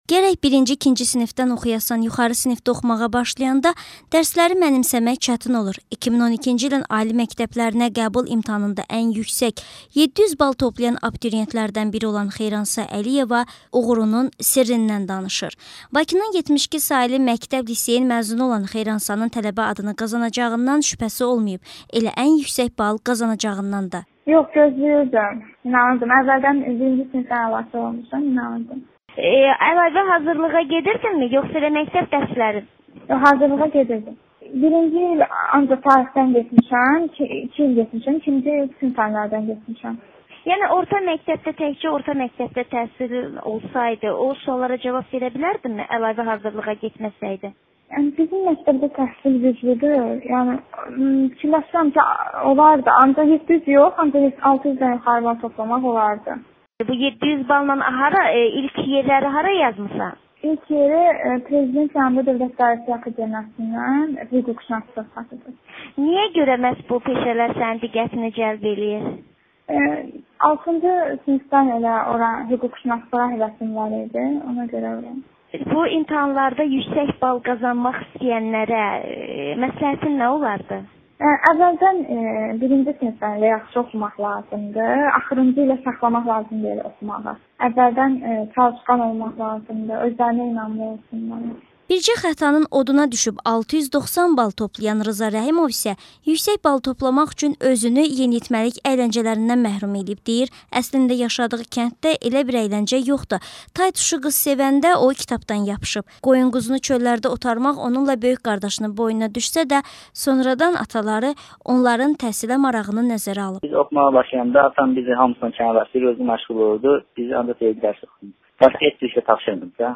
700 bal toplayan abituriyentlə müsahibə